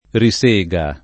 risega [ ri S%g a ]